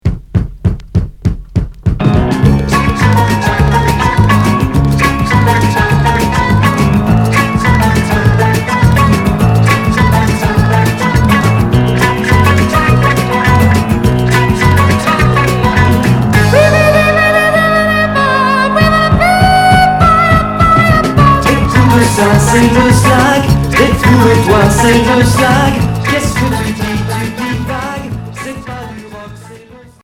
Groove pop